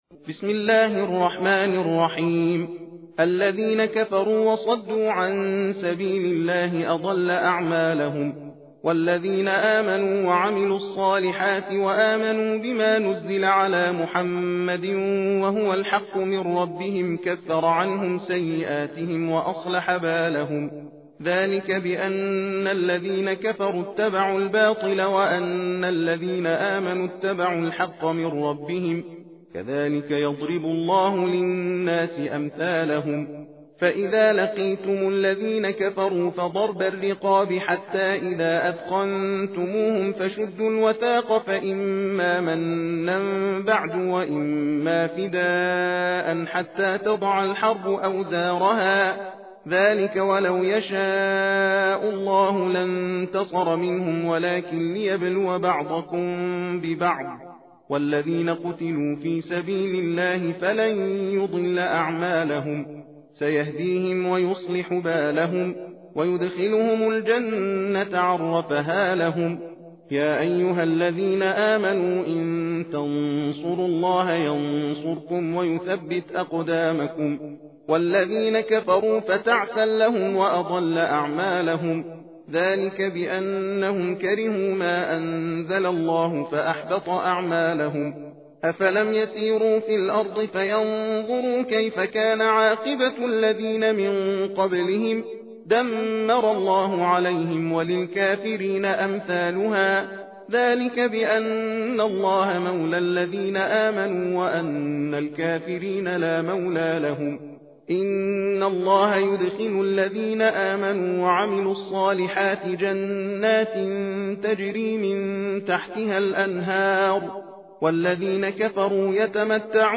تحدیر (تندخوانی) سوره محمد (ص) + فضلیت و برکات سوره محمد
تحدیر روشی از تلاوت قرآن است که قاری در آن علی رغم رعایت کردن قواعد تجوید، از سرعت در خواندن نیز بهره می برد، از این رو در زمان یکسان نسبت به ترتیل و تحقیق تعداد آیات بیشتری تلاوت می شود.به دلیل سرعت بالا در تلاوت از این روش برای مجالس ختم قرآن کریم نیز می توان بهره برد.
تحدیر (تندخوانی) سوره محمد (ص)